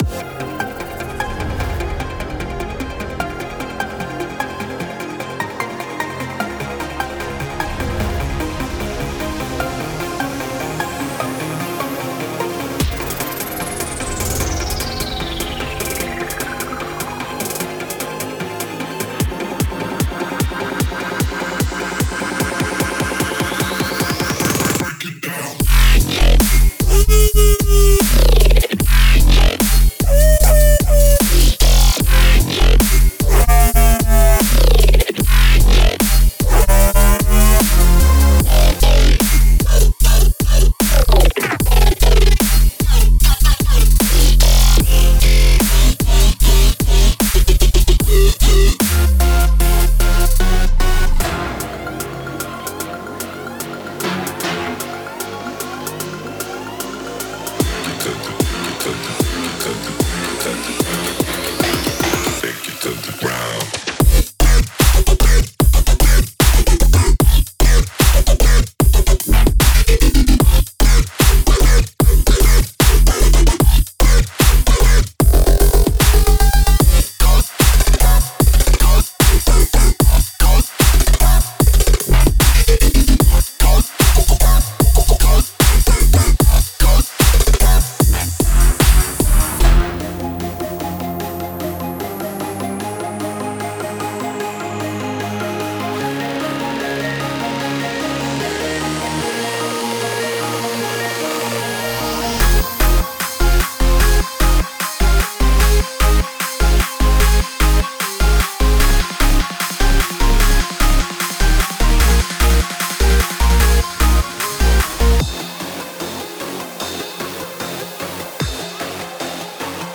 •XFER血清的220个低音预设
•XFER血清的80种合成器预设